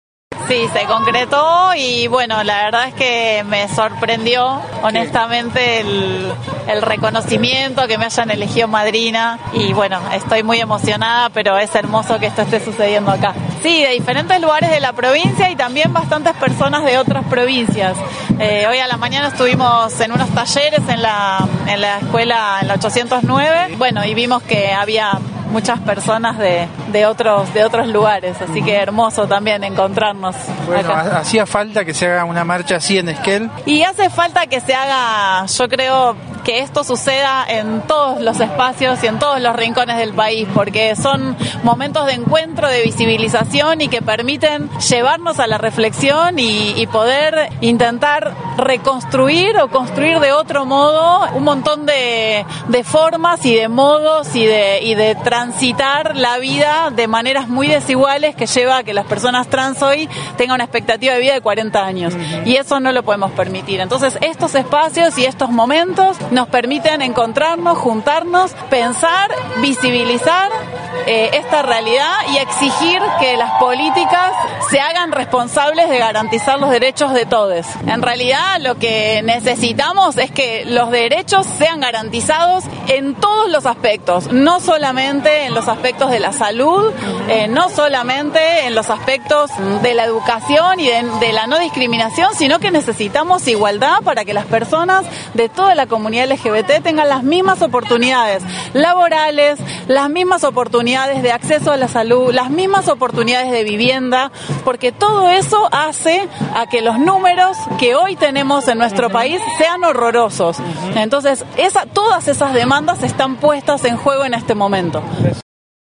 dialogó con Noticias de Esquel